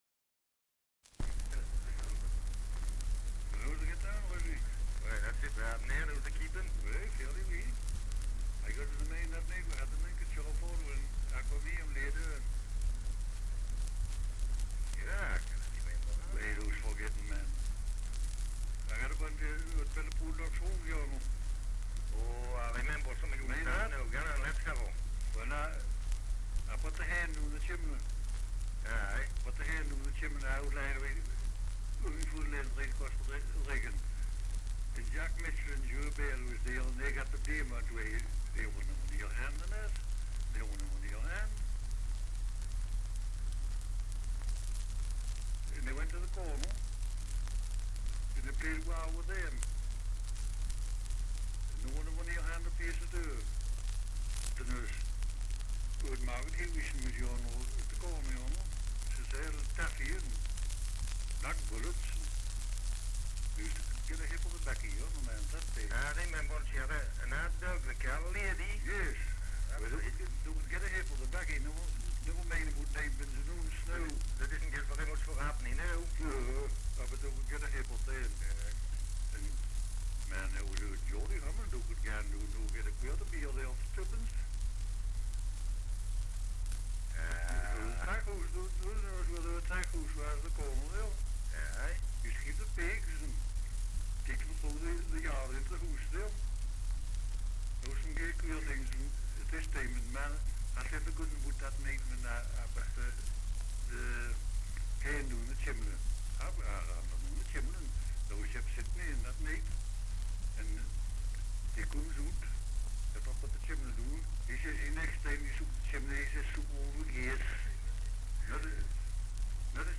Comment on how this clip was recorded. Dialect recording in Wall, Northumberland 78 r.p.m., cellulose nitrate on aluminium